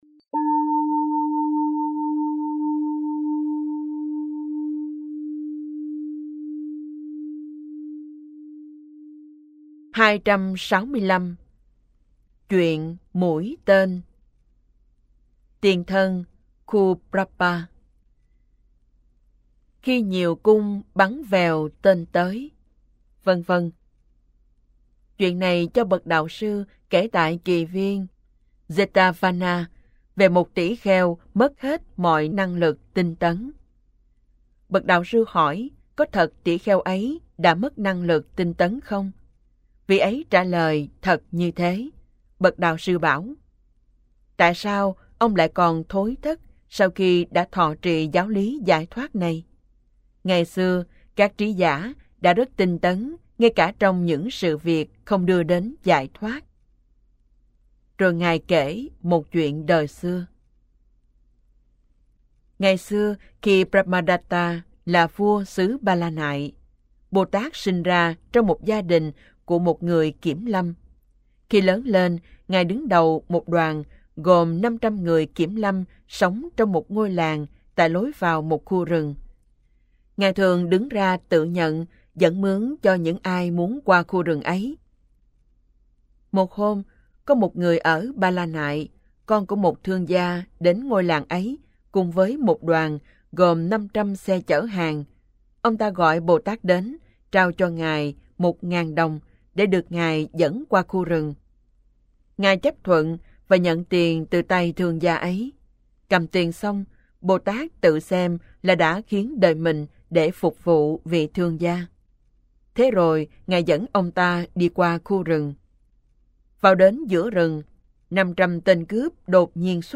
Kinh Tieu Bo 4 - Giong Mien Nam